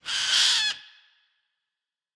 assets / minecraft / sounds / mob / fox / screech4.ogg
screech4.ogg